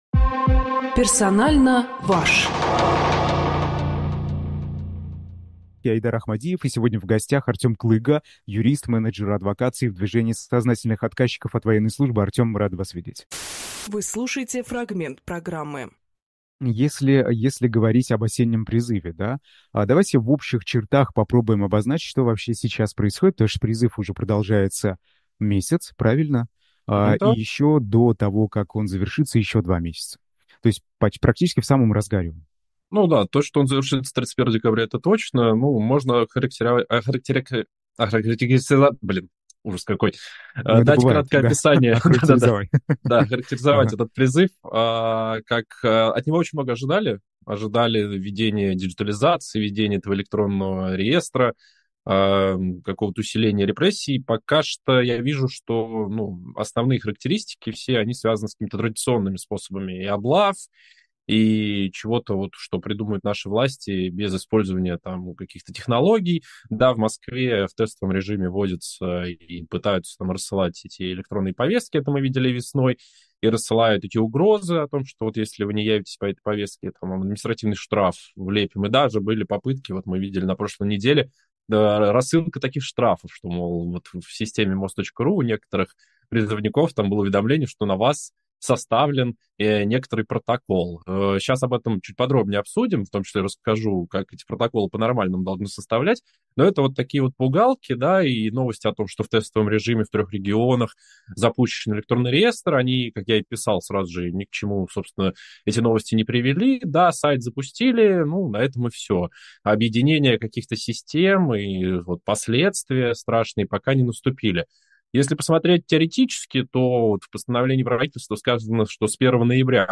Фрагмент эфира от 30.10.24